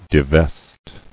[di·vest]